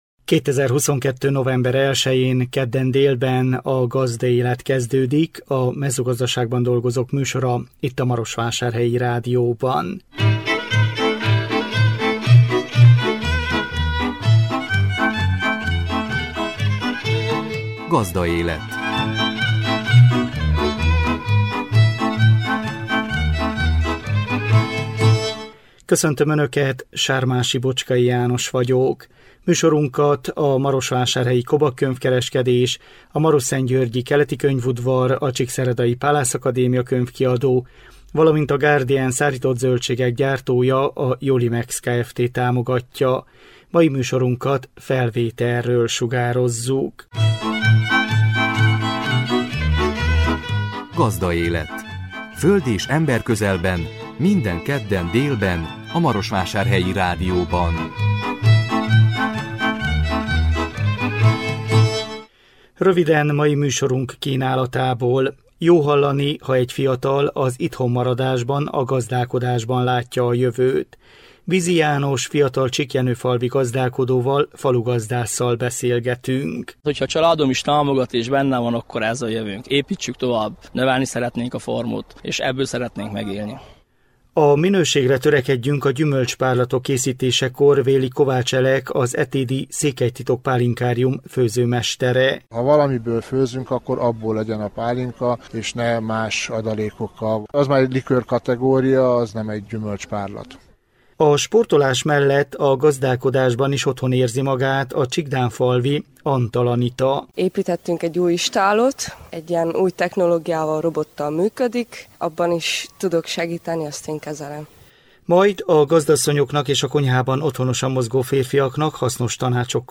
Vele készült interjúnk.